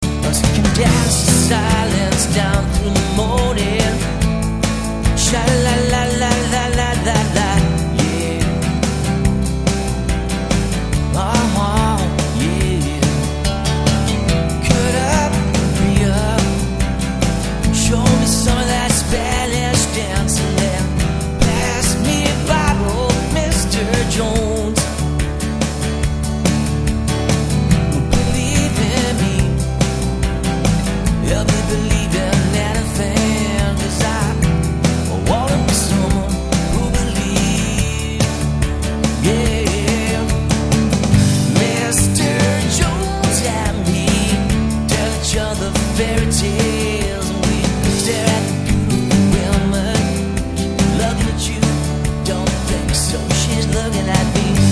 Solo Entertainer - Guitar Vocal